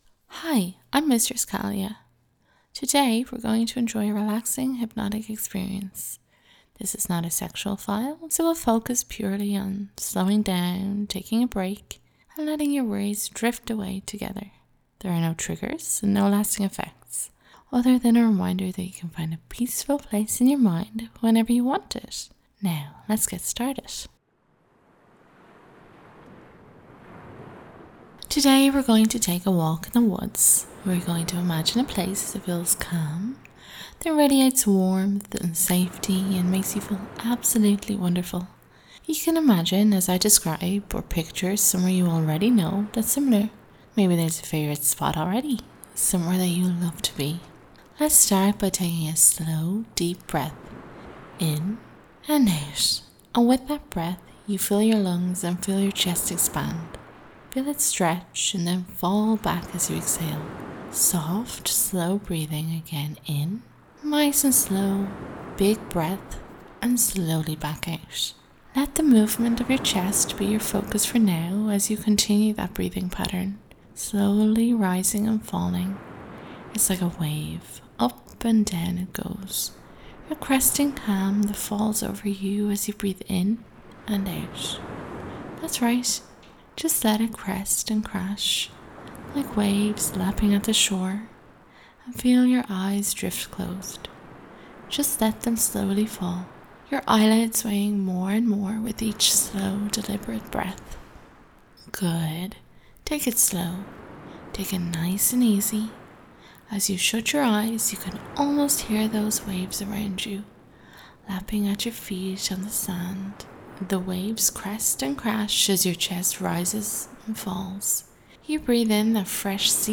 Calm, peaceful hypnosis for relaxation only.